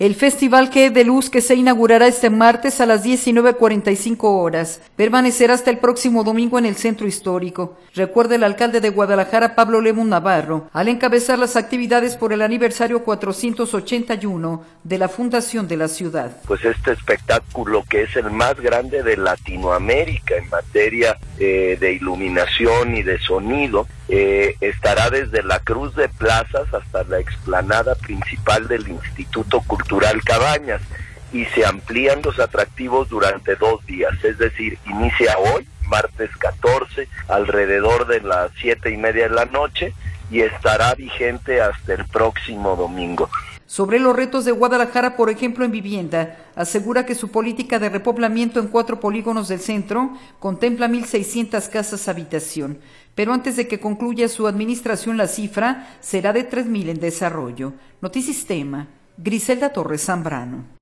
audio El Festival GDLuz que se inaugurará este martes a las 19:45 horas, permanecerá hasta el próximo domingo en el Centro histórico, recuerda el alcalde de Guadalajara, Pablo Lemus Navarro, al encabezar las actividades por el aniversario 481 años de la fundación de la ciudad.